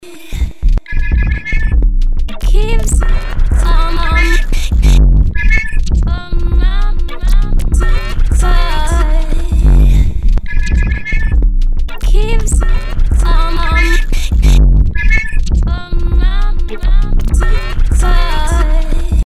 Anstelle der automatisch generierten Slices spiele ich ein paar ausgesuchte Fragmente ein und bearbeite diese bei laufendem Playback mit Timestretching und Effekten. Das Ergebnis zusammen mit Engine 1 ist ein recht wildes Experiment: